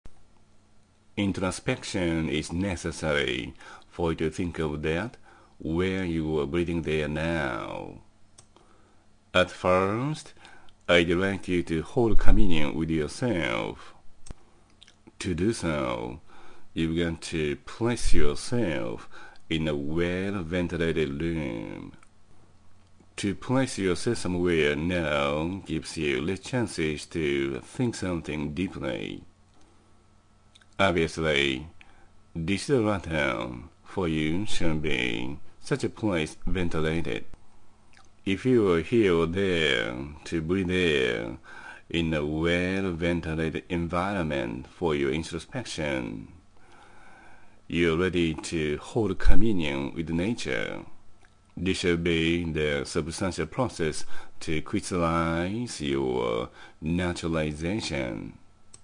英語音声講義 ⇒ Naturalization is crystallized through holding communion with nature.（英語音声講義） <<「 Love is powerful 」前へ 次へ「 I’d like you to live beautifully according to righteousness.